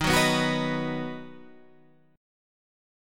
D# Minor Sharp 5th